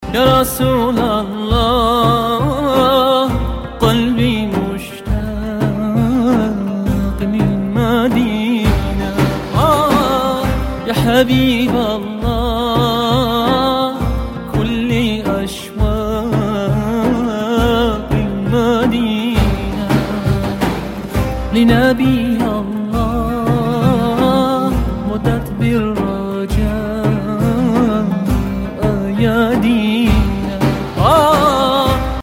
زنگ موبایل معنوی (با کلام) ملایم